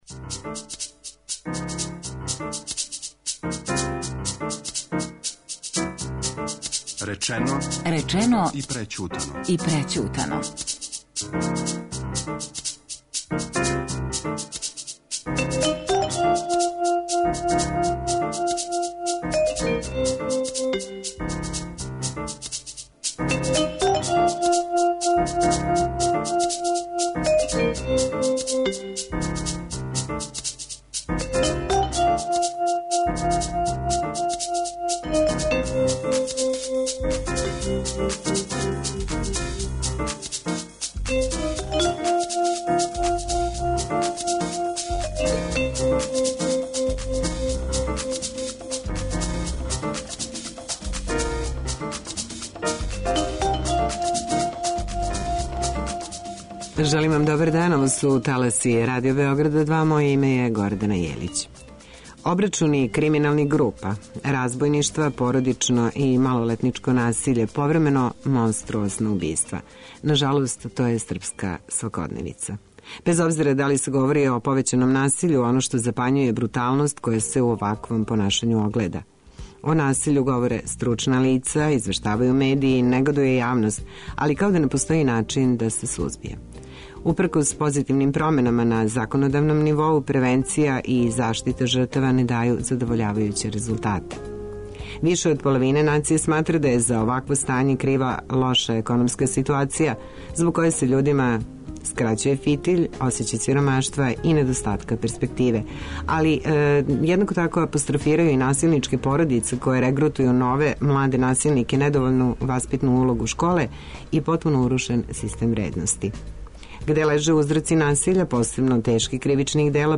Разговор је први пут емитован 25. фебруара 2016 .